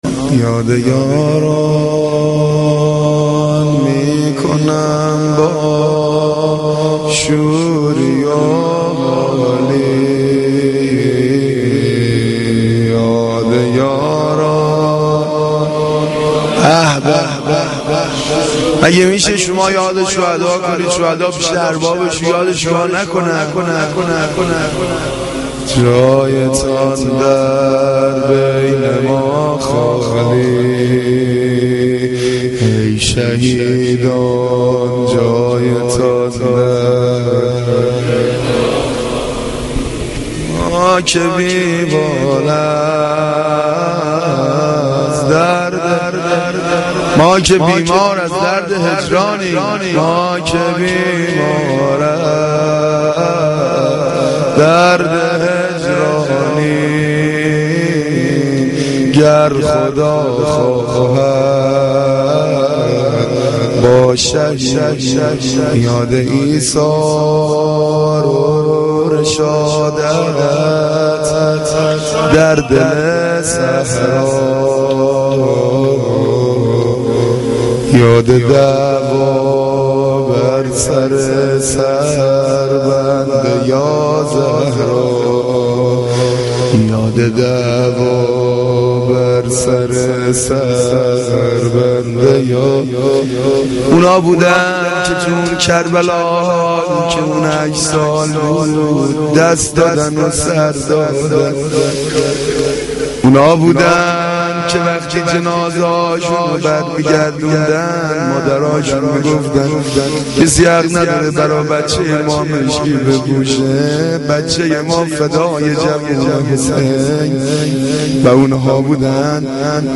مناجات شب دوم